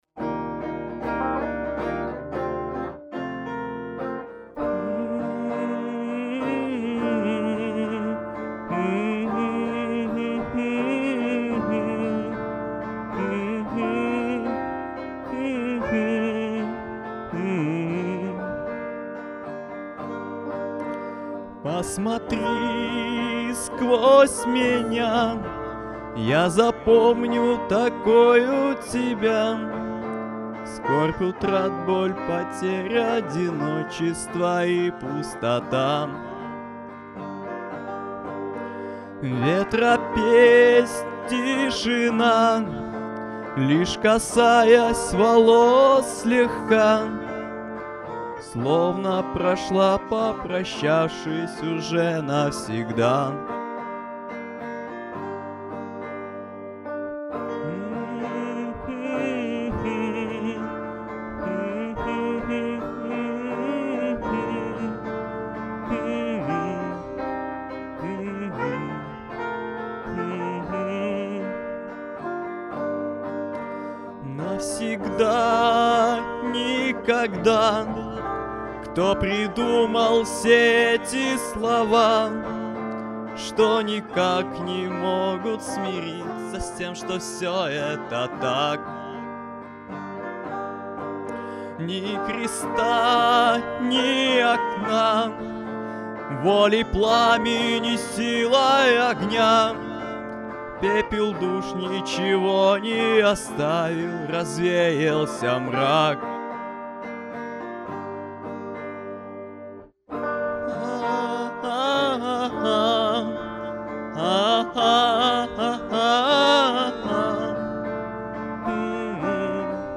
день первокурсника и день учителя
Зал слушал стоя…